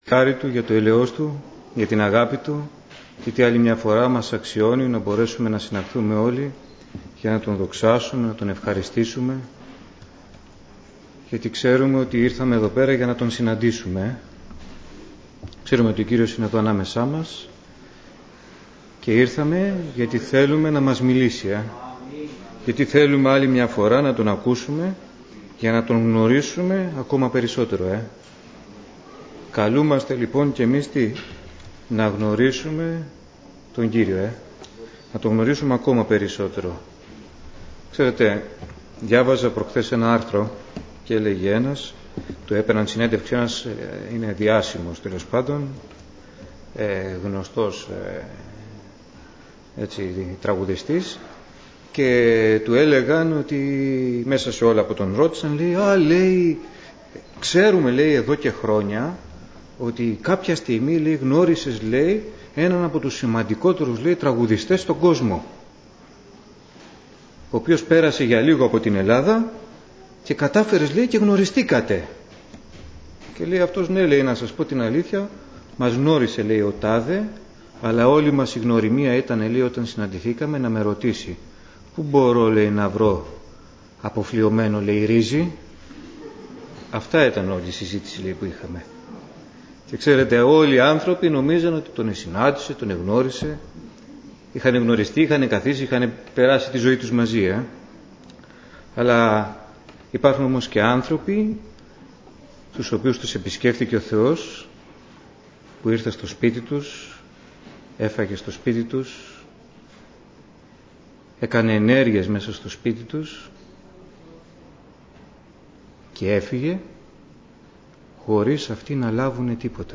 Είναι δίπλα σου Ομιλητής: Διάφοροι Ομιλητές Λεπτομέρειες Σειρά: Κηρύγματα Ημερομηνία: Παρασκευή, 28 Αυγούστου 2020 Εμφανίσεις: 264 Γραφή: Πράξεις των Αποστόλων 12:1-12:19 Λήψη ήχου Λήψη βίντεο